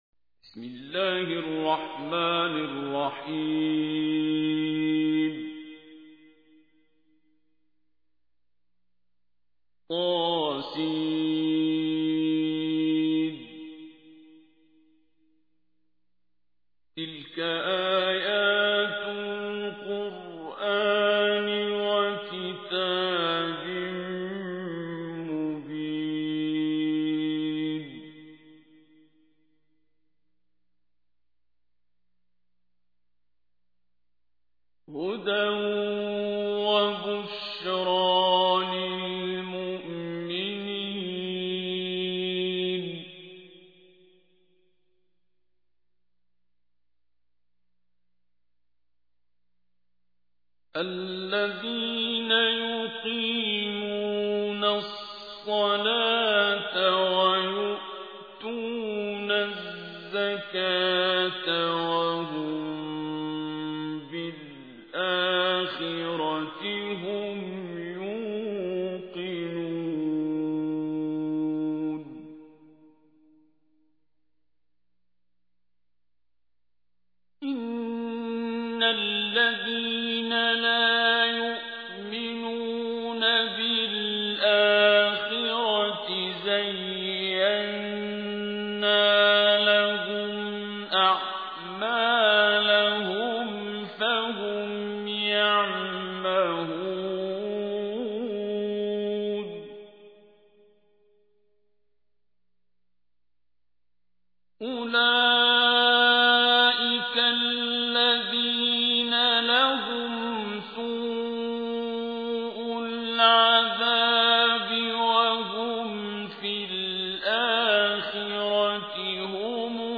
تحميل : 27. سورة النمل / القارئ عبد الباسط عبد الصمد / القرآن الكريم / موقع يا حسين